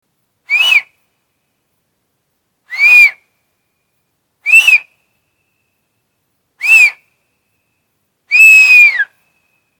Download Whistling sound effect for free.
Whistling